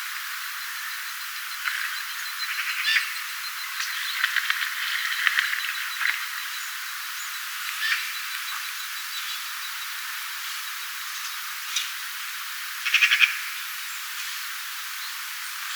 kesäpukuinen koiras sinisorsa lentää ylitse,
siivistä kuuluu tällainen ääni
kesapukuinen_koirassinisorsa_lentaa_ylitse_siivista_kuuluu_tuollainen_aanii.mp3